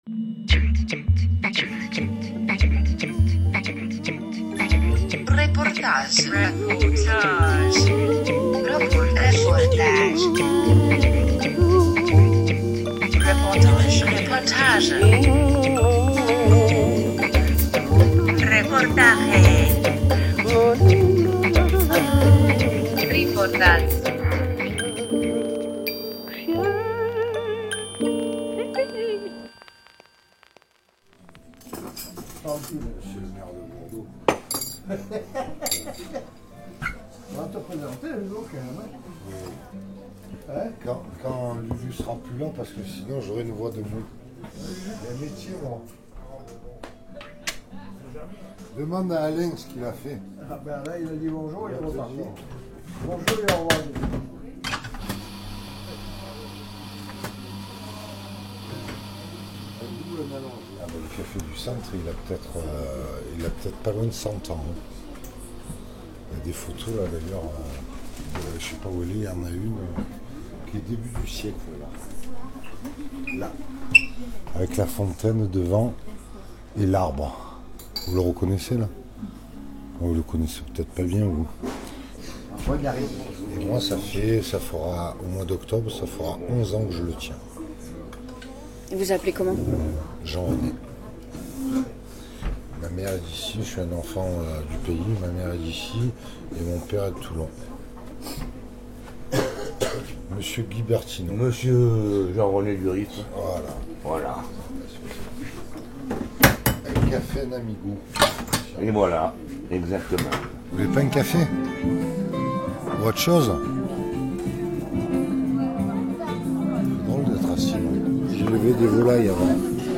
21 août 2017 13:53 | Interview, reportage
RadioLa-Reportage-au-cafe-du-centre-a-Bourdeaux.mp3